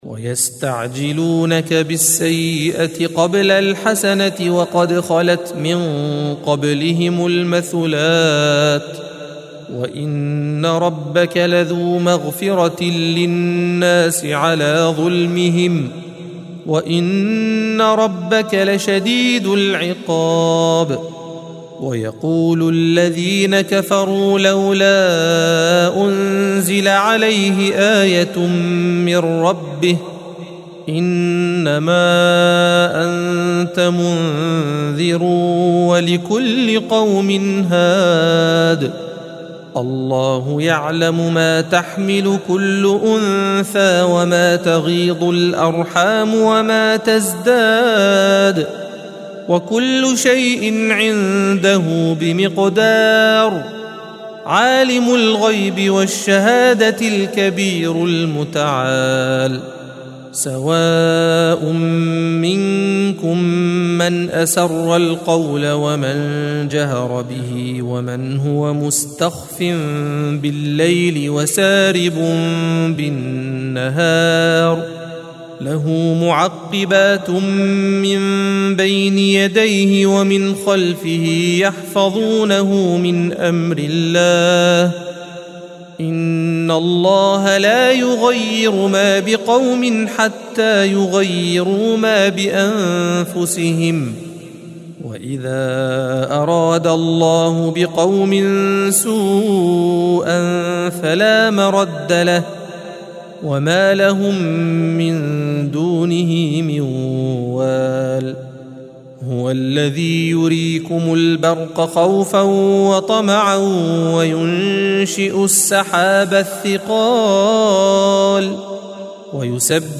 الصفحة 250 - القارئ